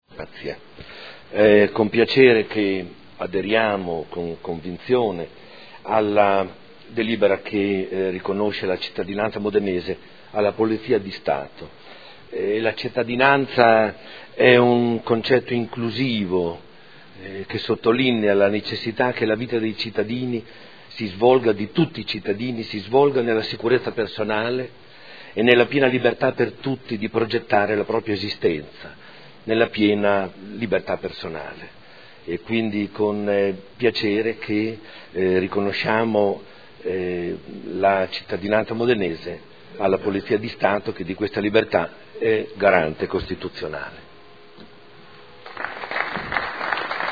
Seduta del 21/09/2015.
Dichiarazioni di voto